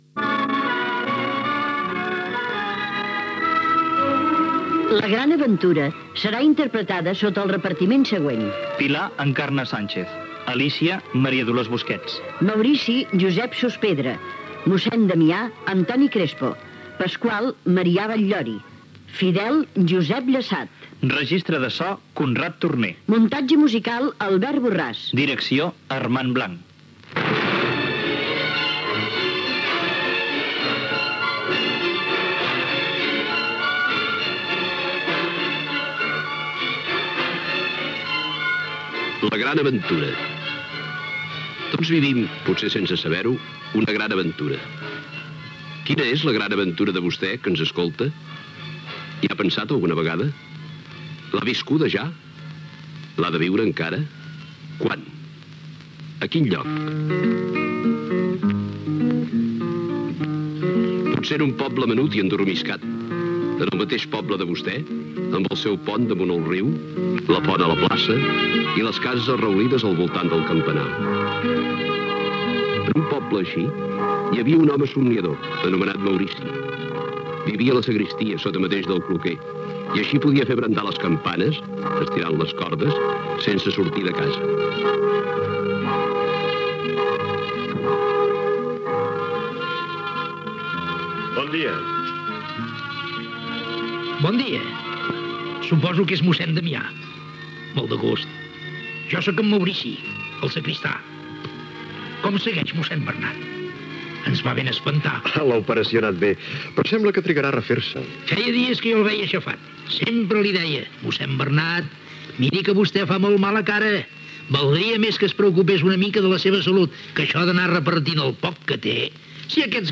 Descripció inicial, diàleg entre el sacristà i el mossèn
Ficció